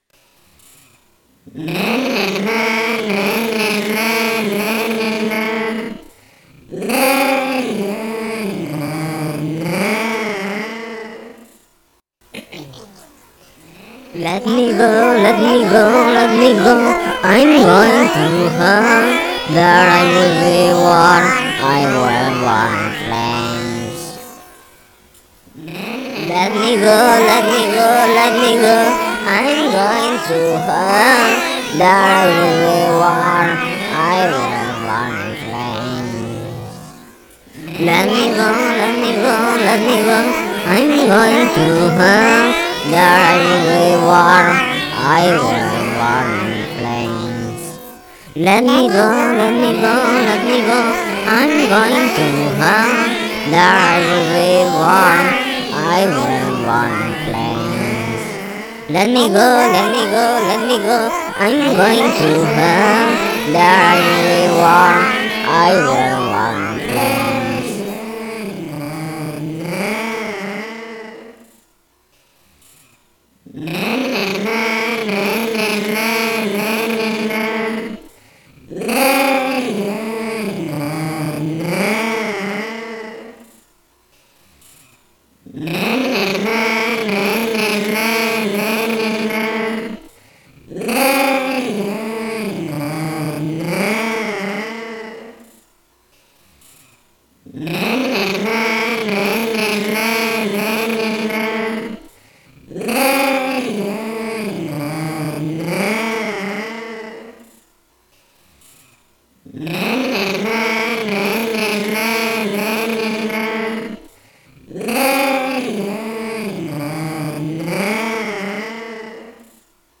Tools: Samson GTrack, Sonar LE.